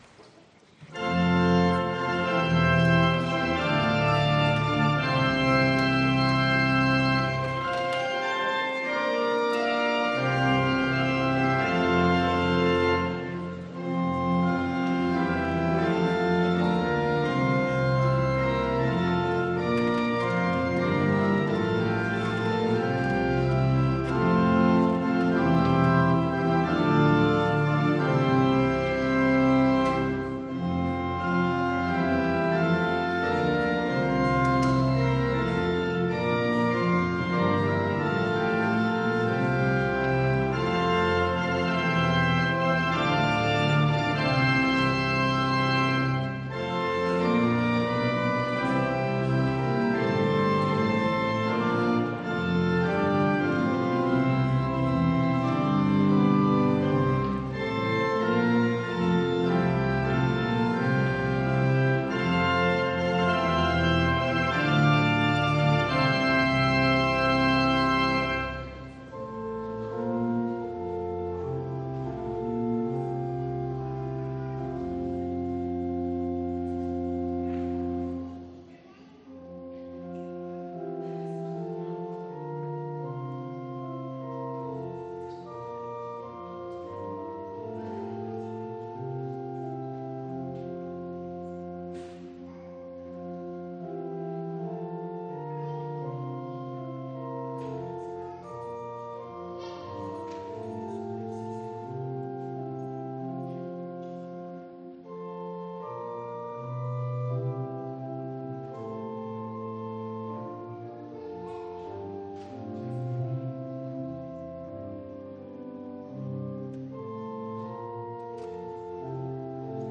Gottesdienst am 20.06.2021